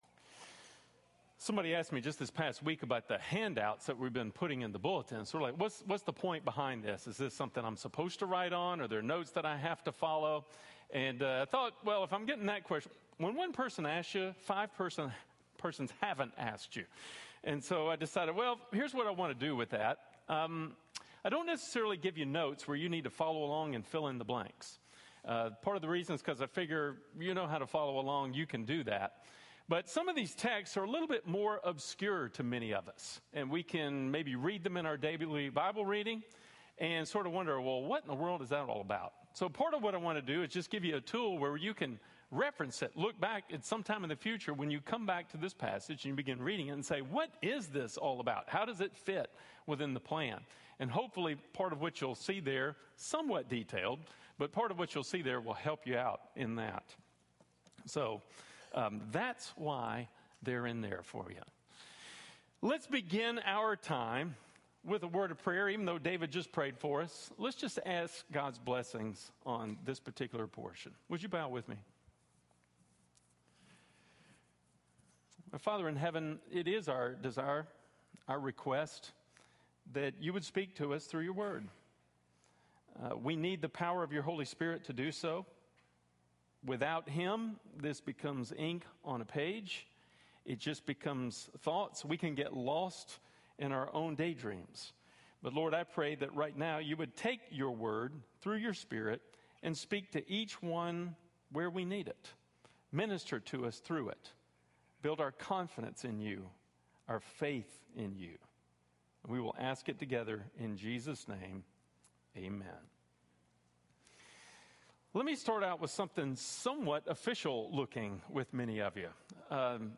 God's Singular Plan - Blue Ridge Bible Church